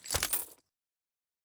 coin_small.wav